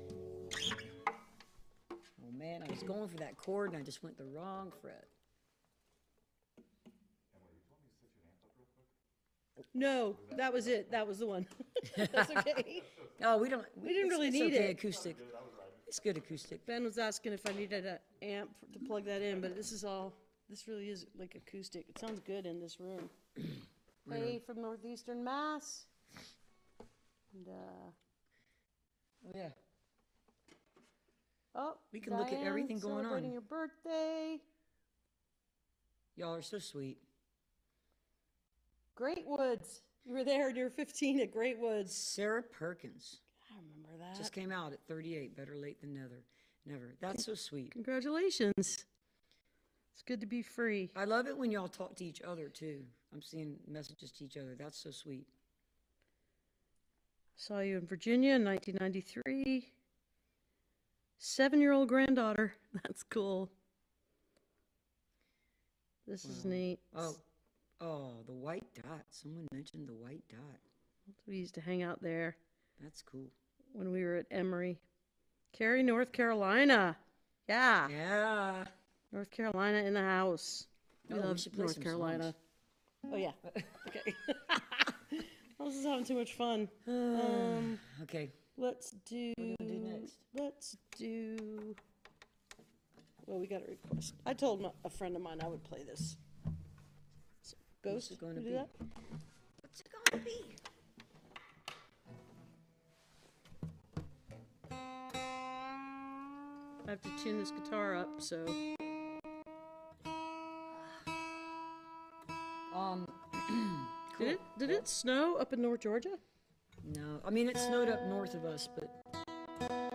(captured from the youtube livestream)
06. talking with the crowd (4:18)